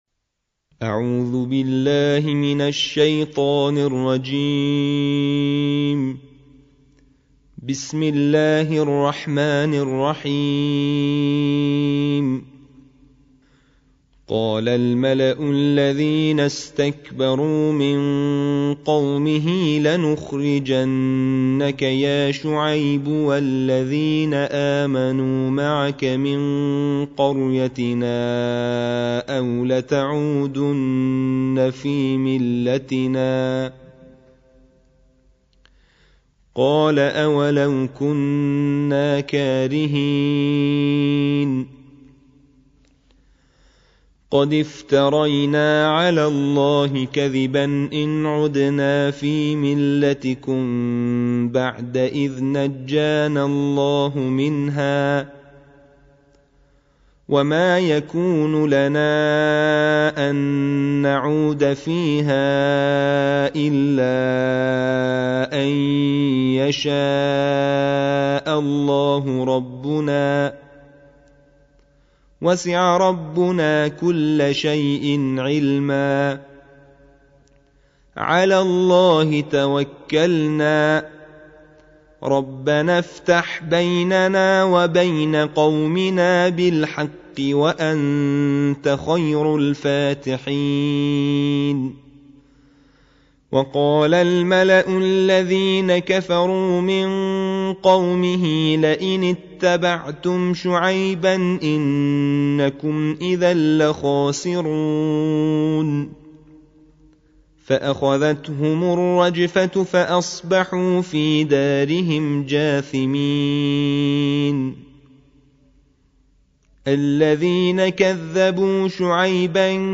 ترتيل القرآن الكريم